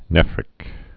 (nĕfrĭk)